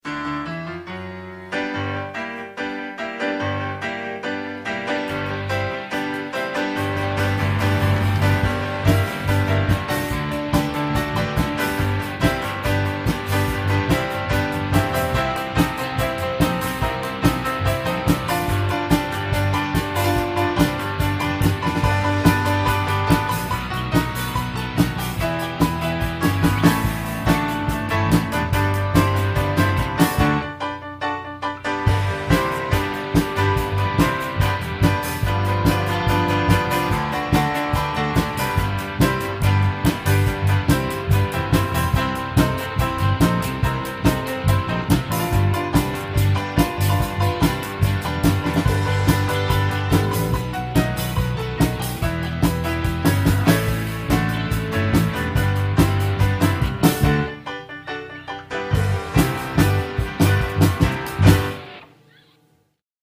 00 - Kompletter Auftritt von DVD ungeschnitten